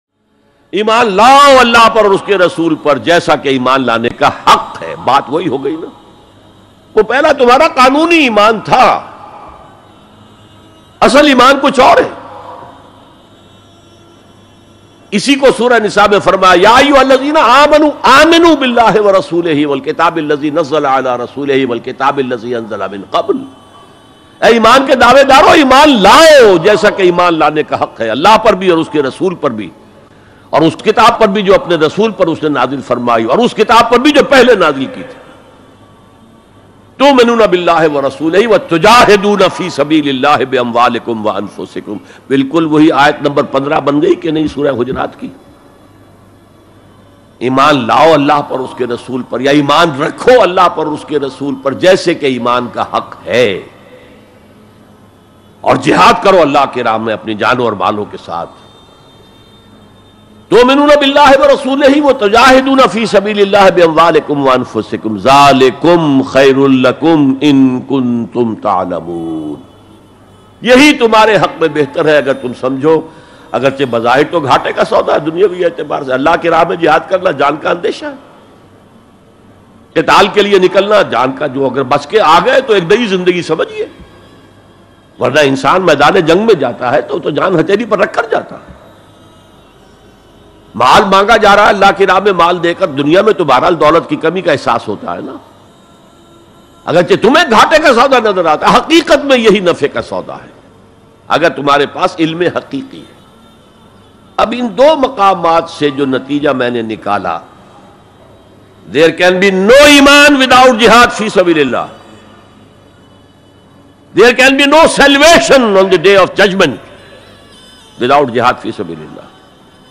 JIHAD Ki Asal Haqeeqat Bayan MP3 Download Dr Israr Ahmed